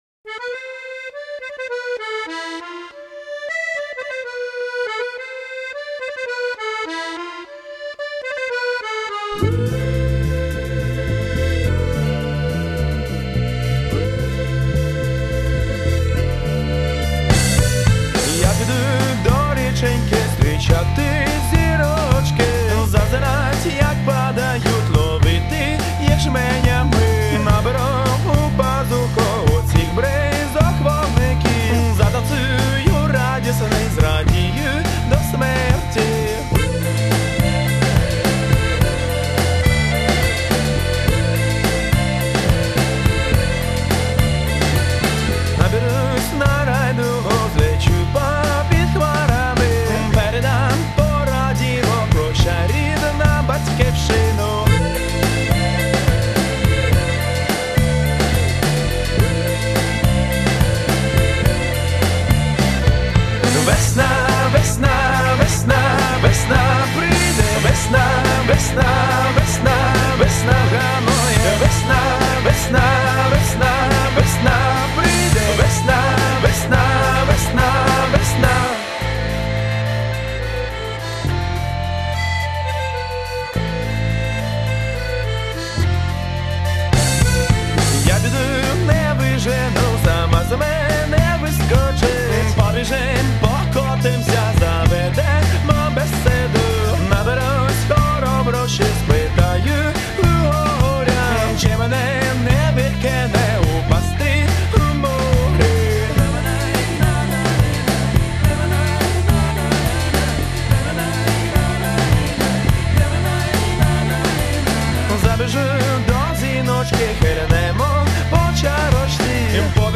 Рубрика: Рок